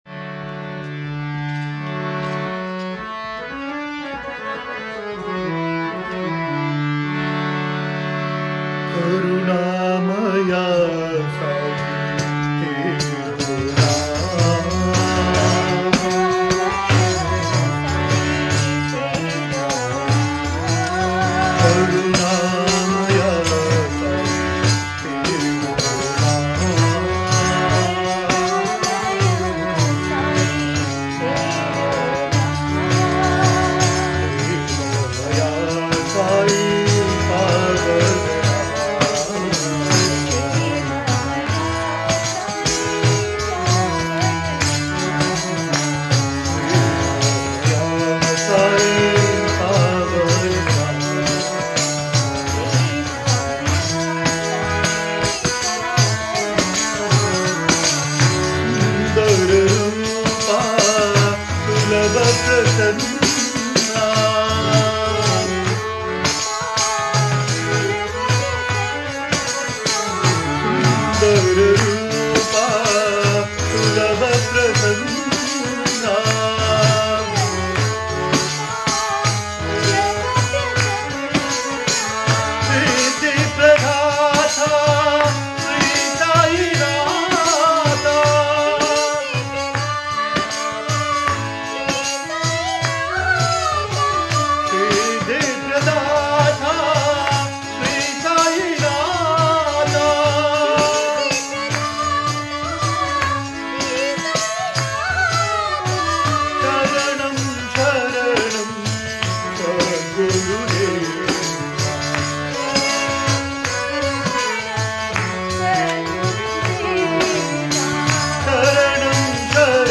1. Devotional Songs
~ Kalyani / Yaman 8 Beat  Men - 1 Pancham  Women - 5 Pancham
~ Kalyani / Yaman
8 Beat / Keherwa / Adi
1 Pancham / C
5 Pancham / G
Lowest Note: d2 / A (lower octave)
Highest Note: G2 / E (higher octave)